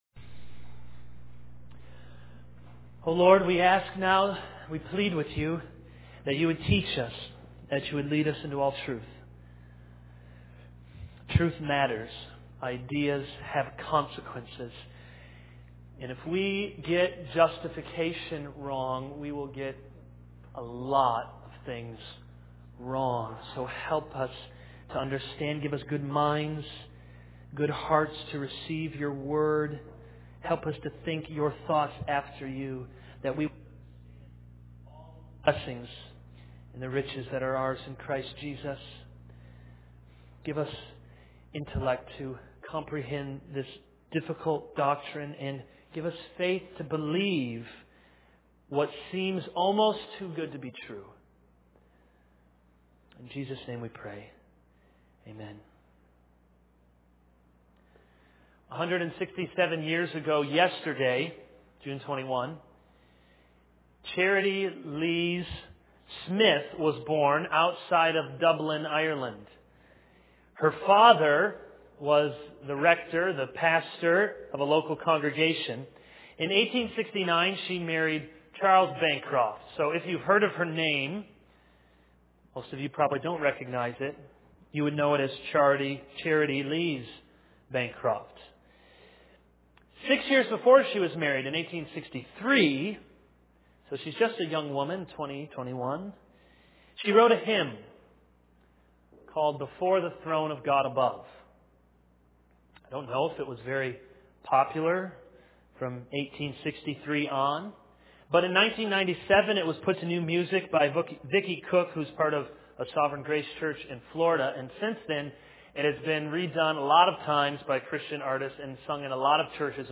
This is a sermon on 2 Corinthians 5:21.